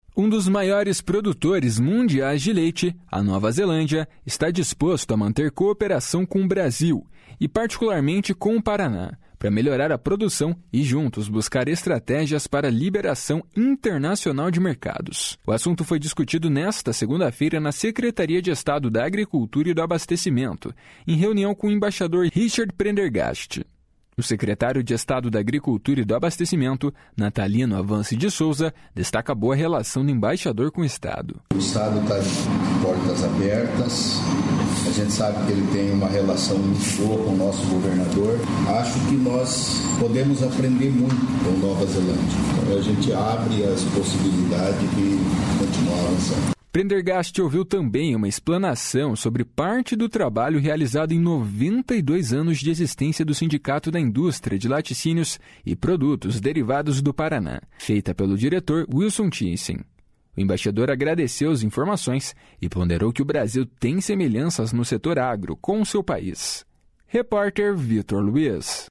O assunto foi discutido nesta segunda-feira na Secretaria de Estado da Agricultura e do Abastecimento, em reunião com o embaixador Richard Prendergast. O secretário de Estado da Agricultura e do Abastecimento, Natalino Avance de Souza, destaca a boa relação do embaixador com o Estado. // SONORA NATALINO AVANCE //